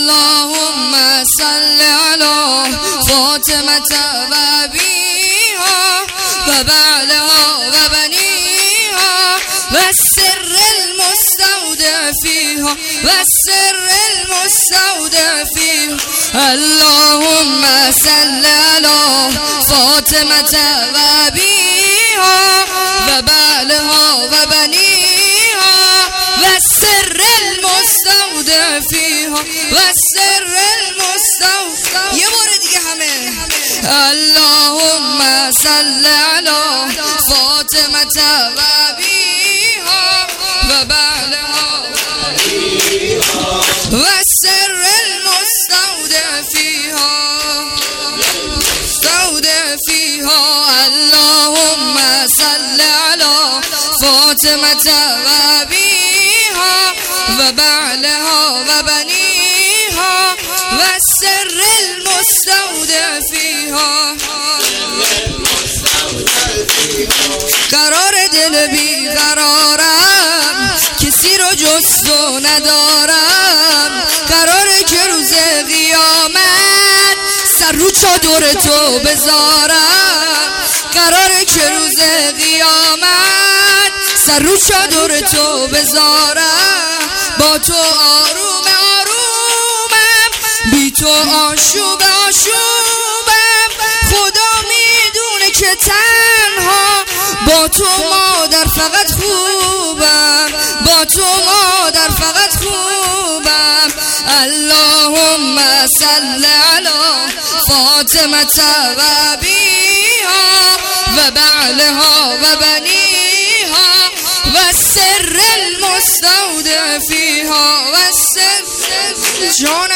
قم جشن میلاد حضرت زهرا (س) 99 جشن میلاد حضرت زهرا